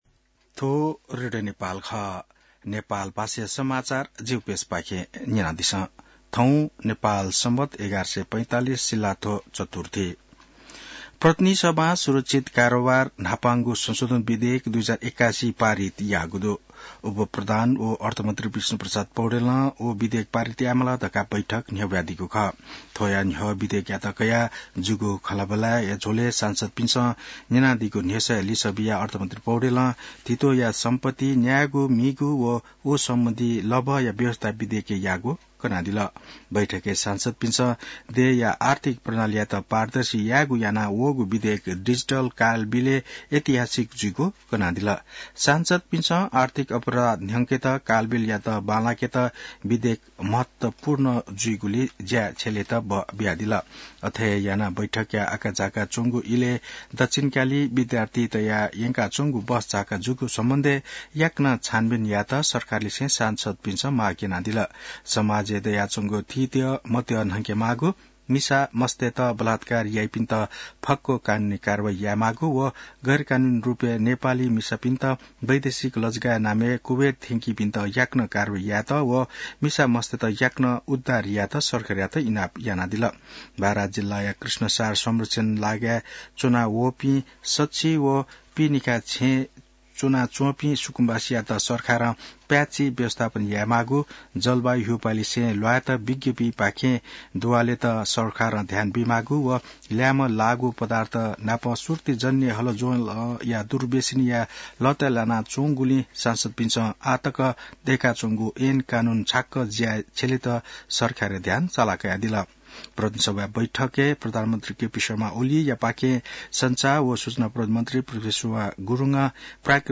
नेपाल भाषामा समाचार : ३० माघ , २०८१